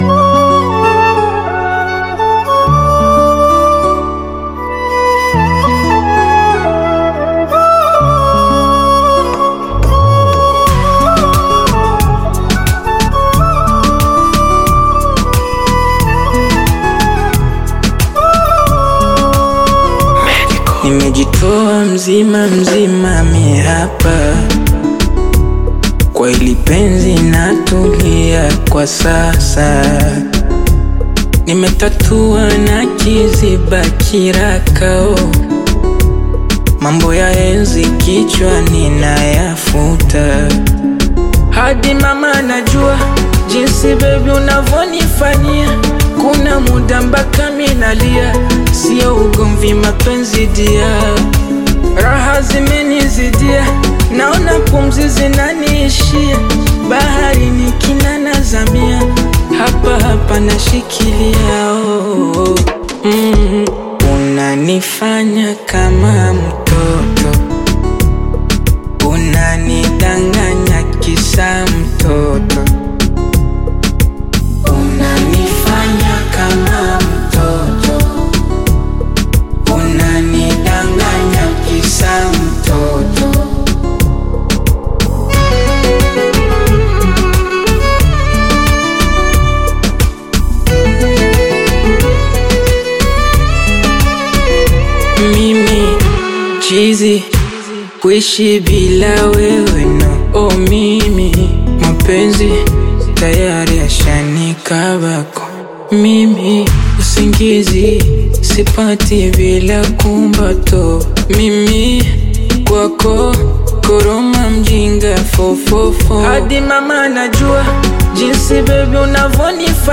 heartfelt Tanzanian Bongo Flava/Afro-Pop single
blends melodic hooks with modern Afro-inspired rhythms
expressive delivery